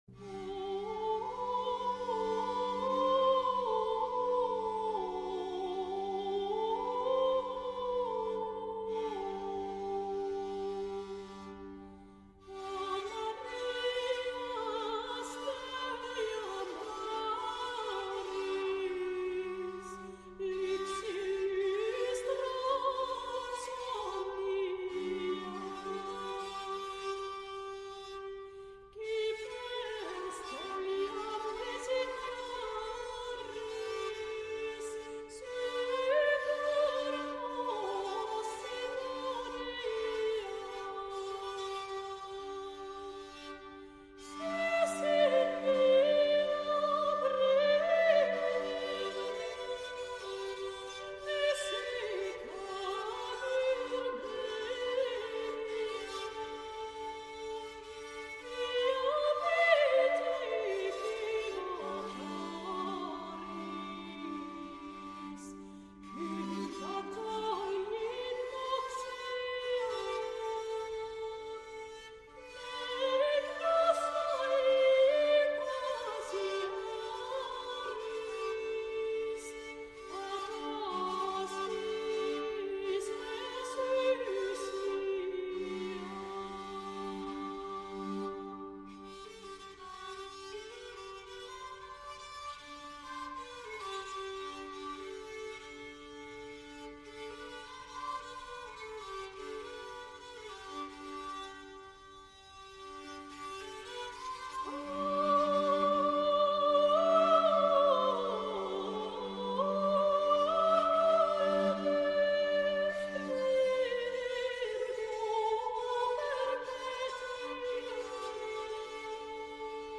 Il canto sacro nel nome di Gregorio Magno